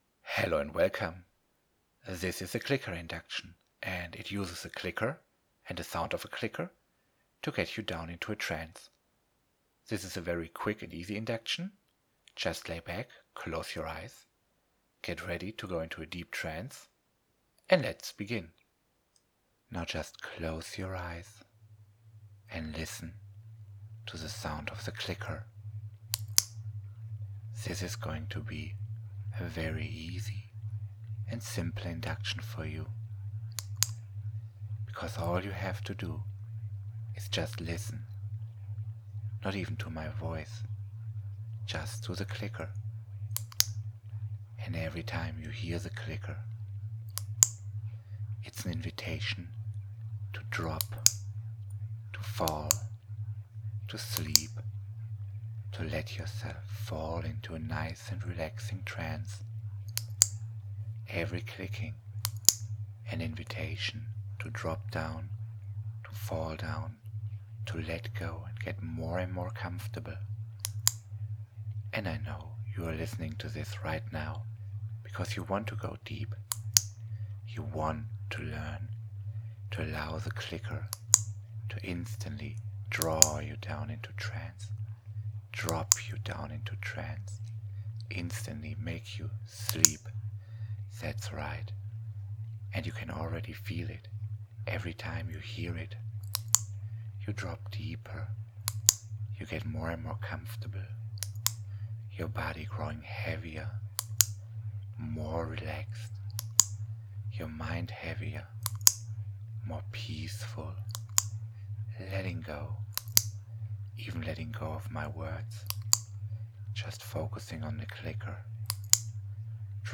This is a quick induction that uses the sound of a clicker to drop you down into a deep trance.
Clicker Induction